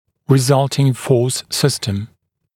[rɪ’zʌltɪŋ fɔːs ‘sɪstəm][ри’залтин фо:с ‘систэм]результирующая система сил